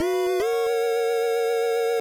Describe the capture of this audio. This file is an audio rip from a(n) NES game.